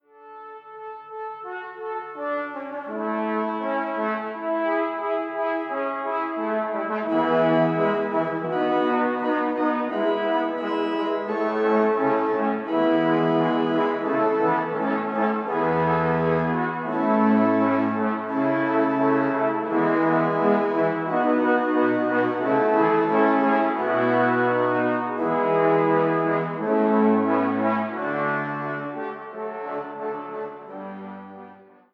für vier Posaunen und Orgel